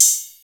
Open Hats
BWB WAVE 5 HAT OPEN (2).wav